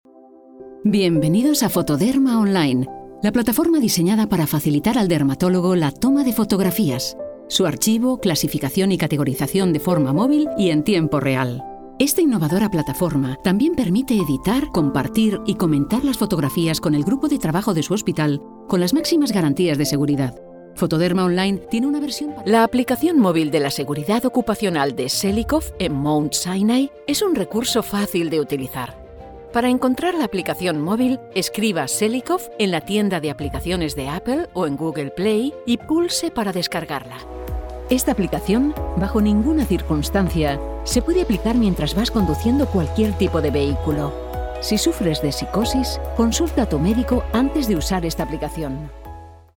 APP解说-样音集合